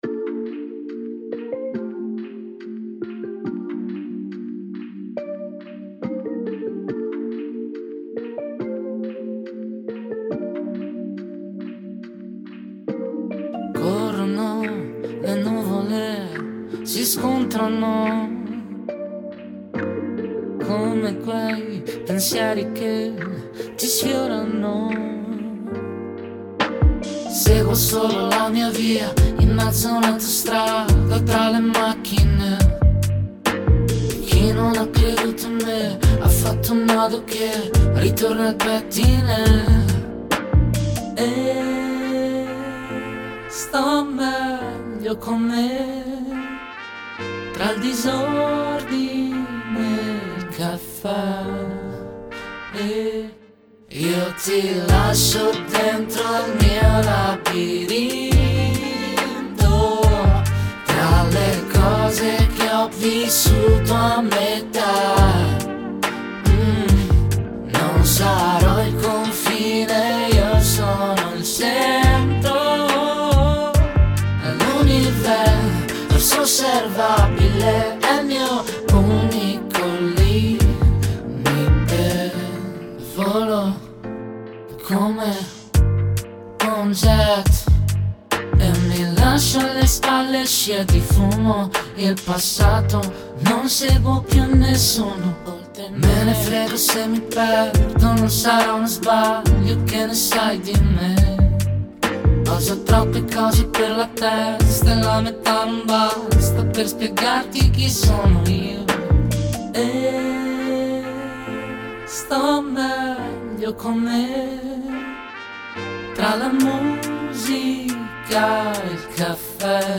• Bpm70
• GenereSoul, RnB, Pop
• InterpreteUomo, Donna